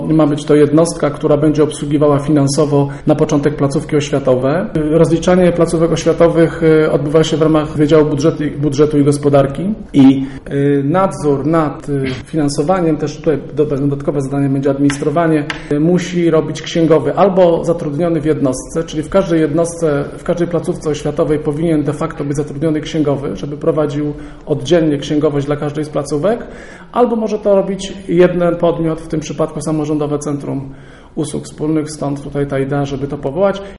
Przyjęli oni jednak uchwałę dotyczącą powstania Samorządowego Centrum Usług Wspólnych, mówi Robert Luchowski.